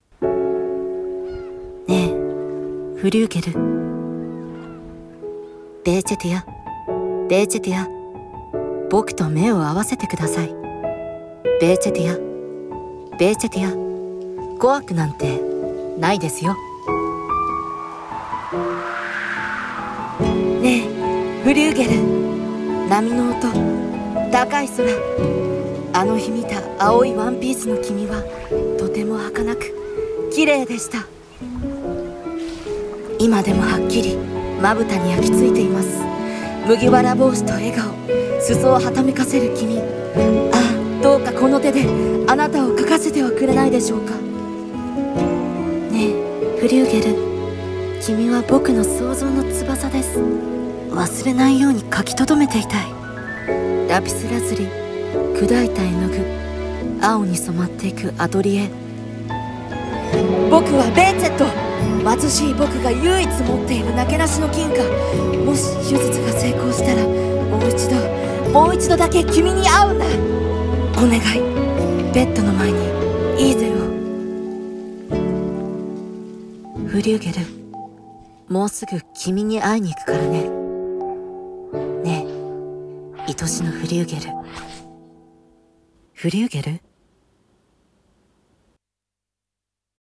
CM風声劇